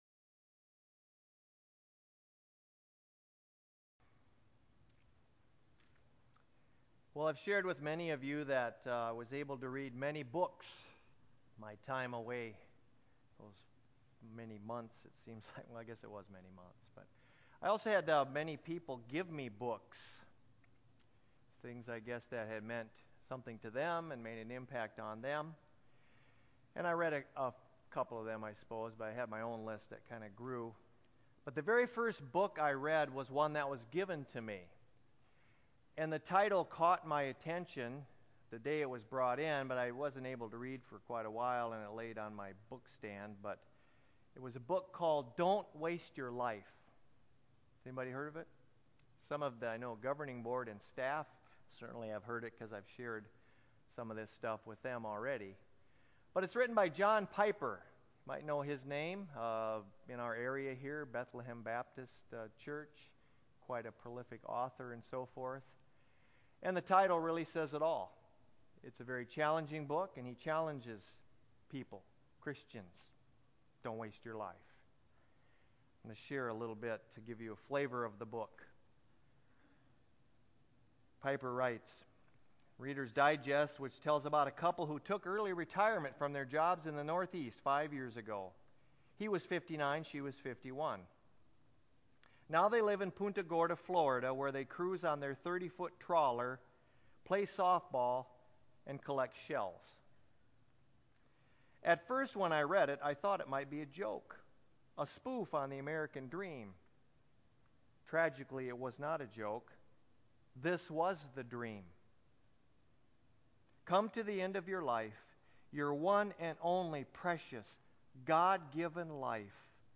church-sermon8.4-CD.mp3